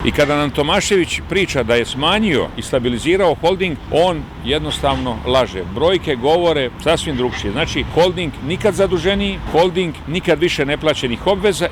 Aktualni zagrebački gradonačelnik Tomislav Tomašević i kandidat platforme Možemo! za drugi mandat u metropoli Media servisu predstavio je svoj program.